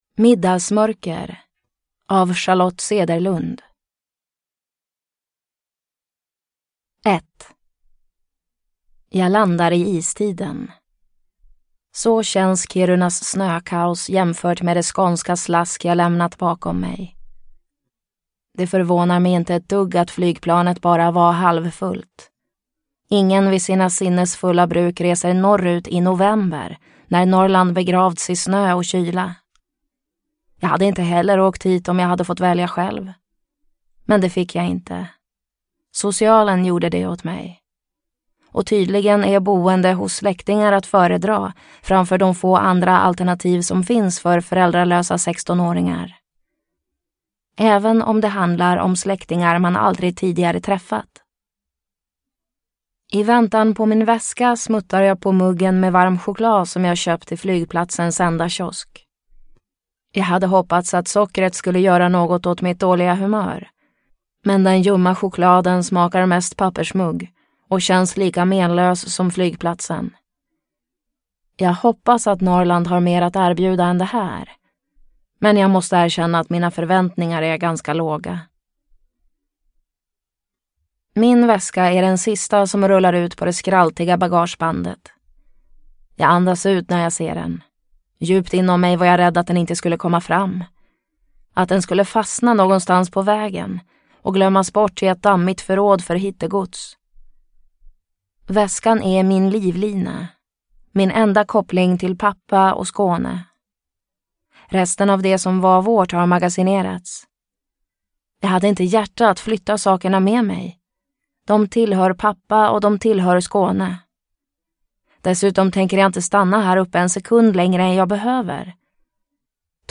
Middagsmörker – Ljudbok – Laddas ner